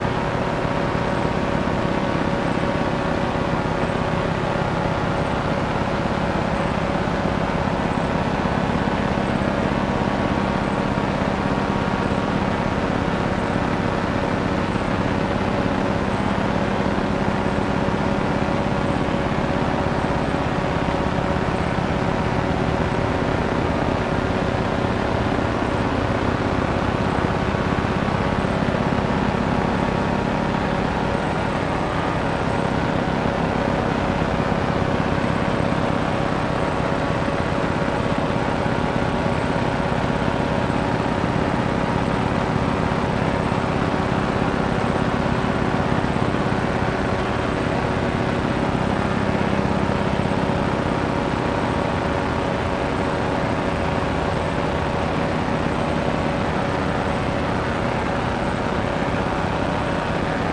电子静态噪音音
描述：电子设备的电磁记录（计算机？）。记录了长时间的静音。
Tag: 电子 嗡嗡声 静电 计算机 轰鸣 嗡嗡声 电动 噪声 音响